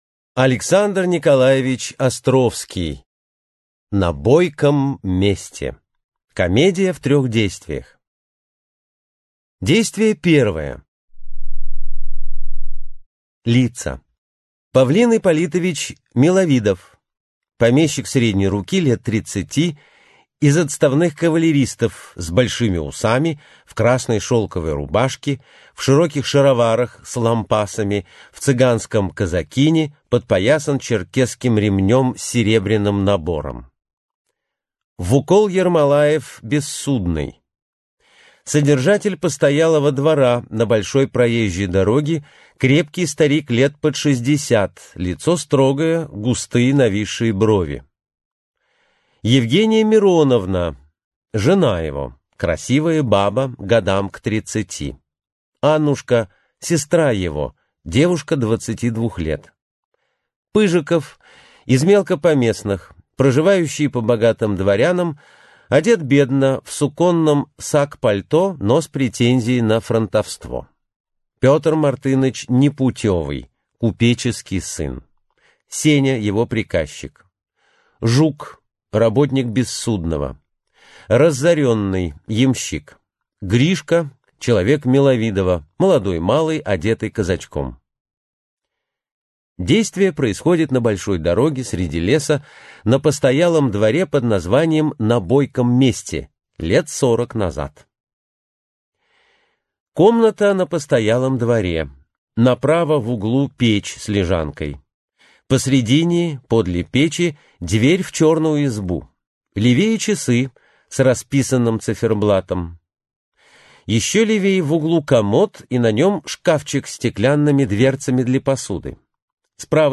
Аудиокнига На бойком месте | Библиотека аудиокниг
Прослушать и бесплатно скачать фрагмент аудиокниги